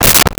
Car Door Close 04
Car Door Close 04.wav